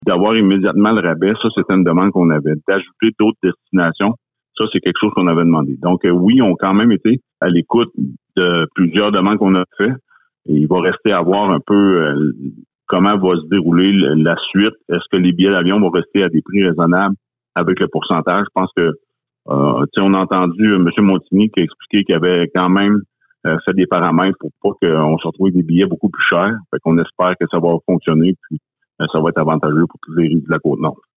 Écoutez l’entrevue complète réalisée avec Martin St-Laurent, maire de Fermont :